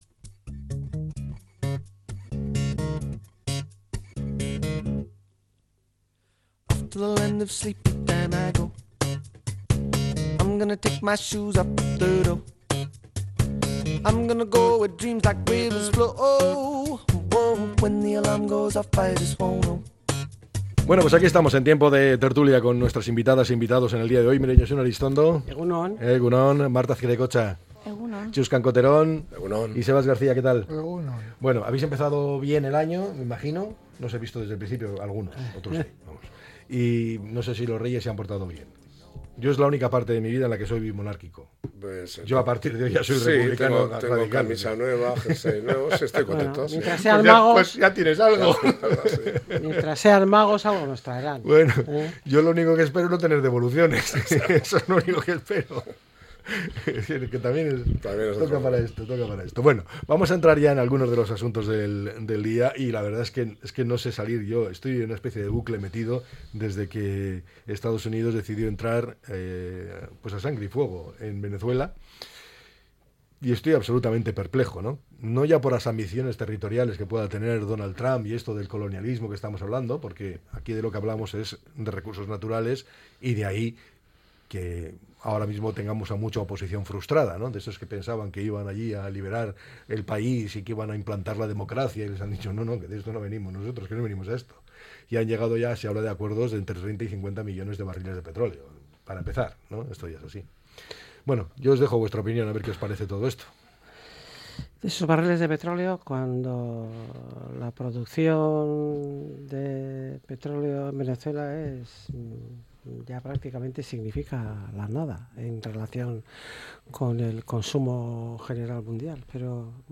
La Tertulia 07-01-26.